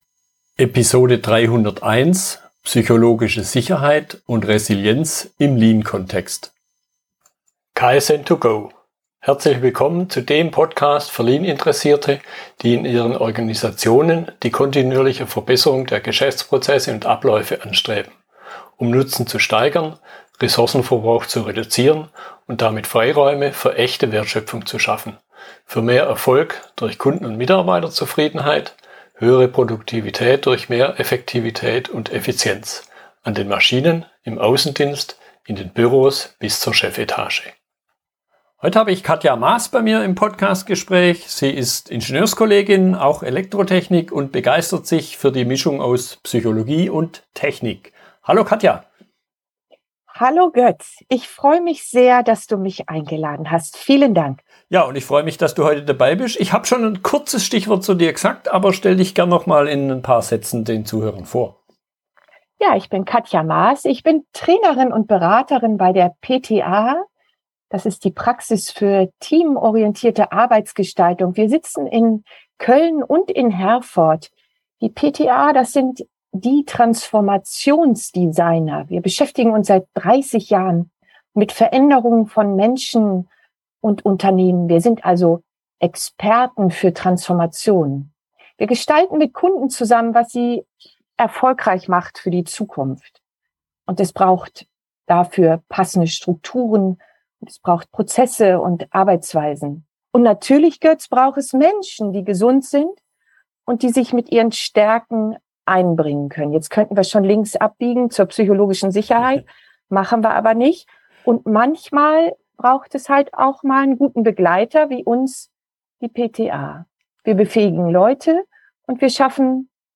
Fragestellungen aus der Unterhaltung